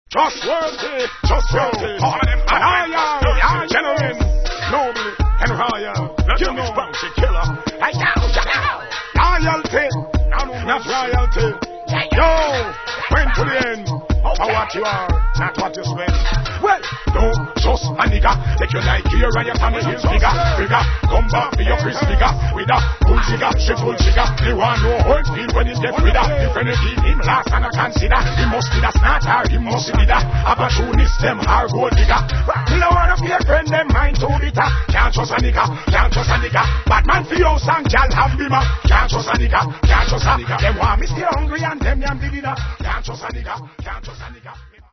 TOP 10 DANCEHALL